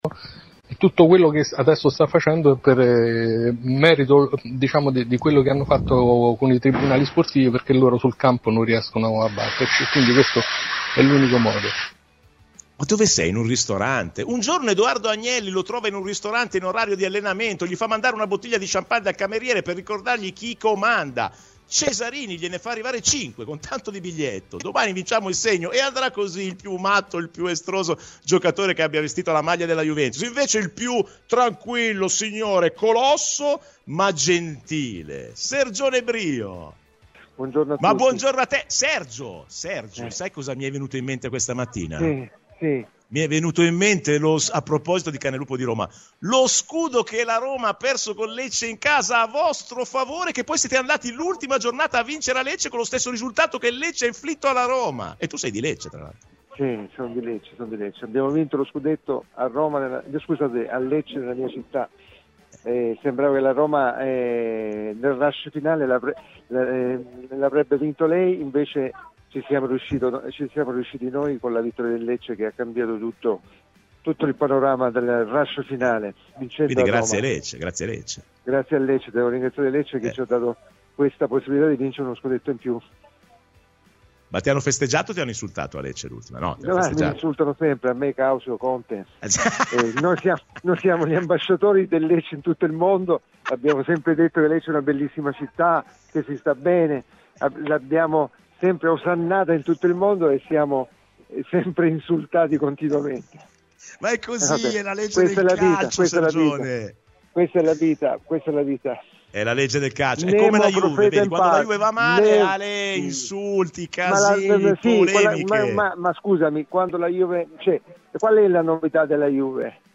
Nel podcast l'intervista integrale